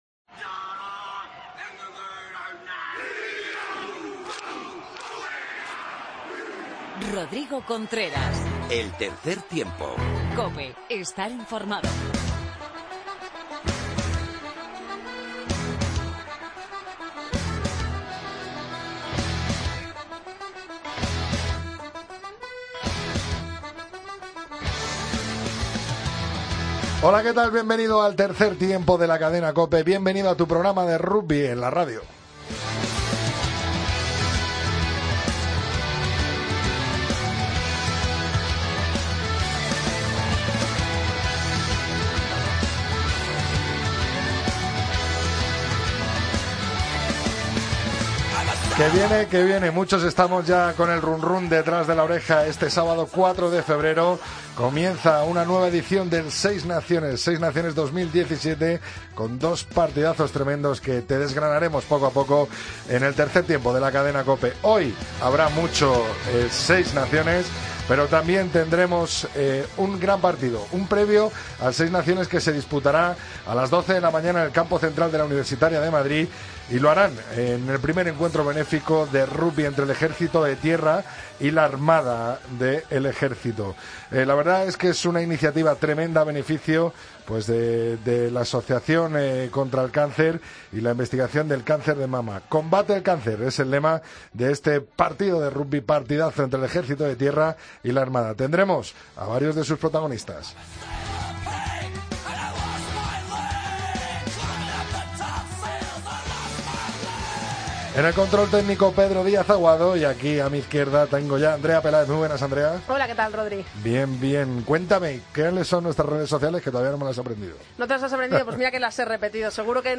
Tertulia de actualidad